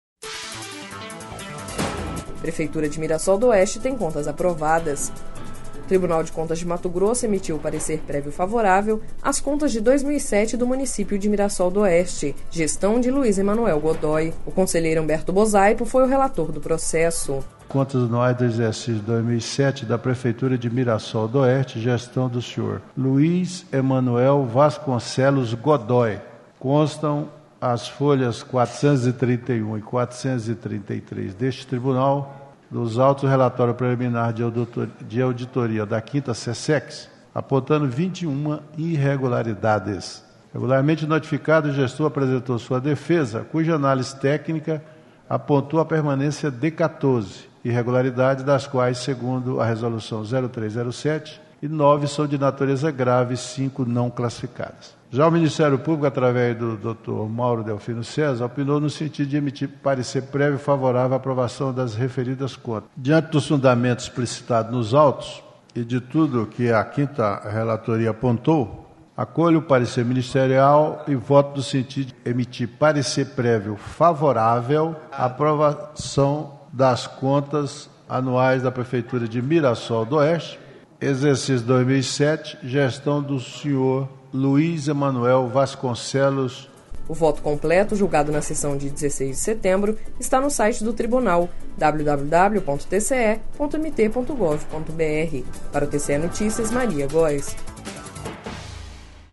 Sonora: Humberto Bosaipo - conselheiro do TCE-MT